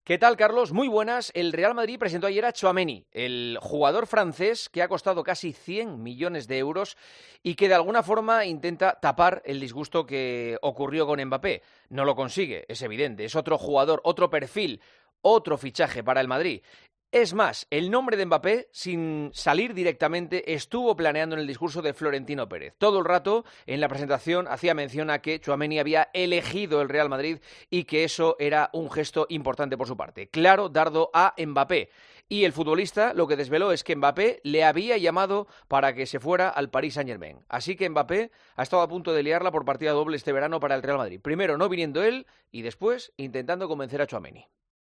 El comentario de Juanma Castaño
El director de 'El Partidazo de COPE' analiza la actualidad deportiva en 'Herrera en COPE'